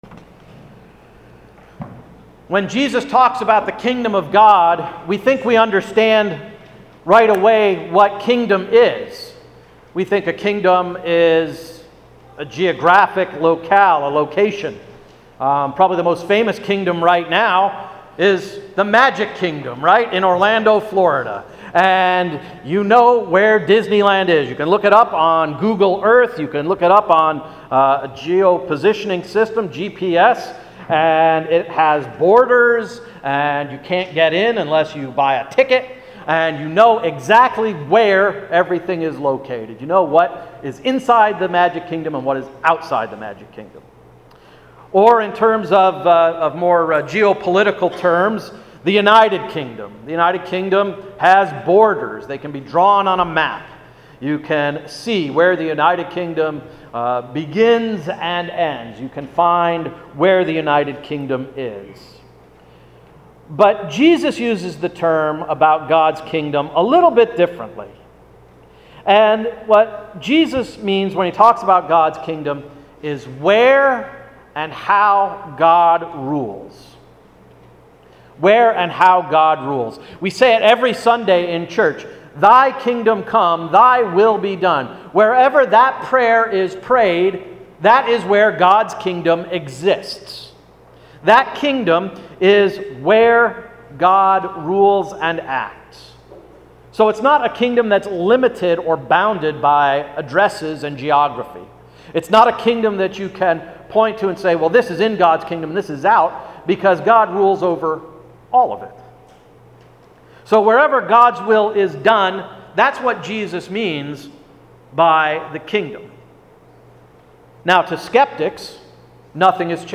Sermon of June 14, 2015–“The Waiting Room”